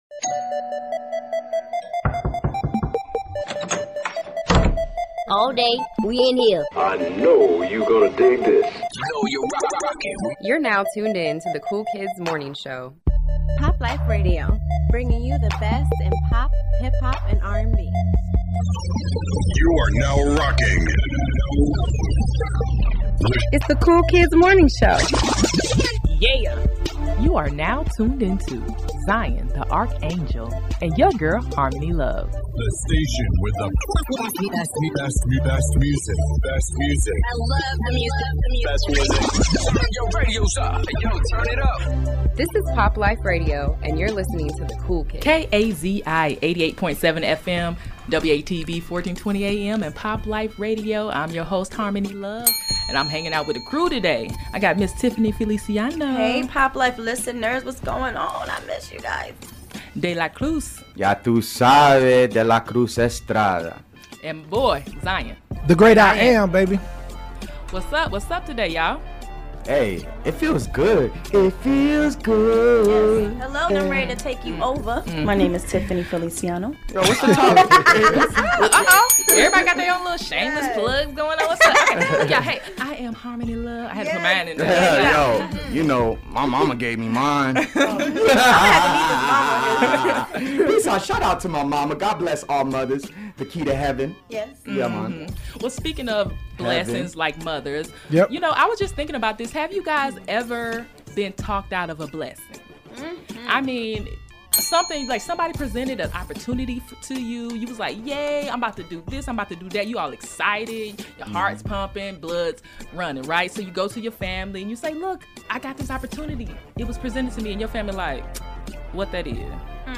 The Cool Kids Morning Show interview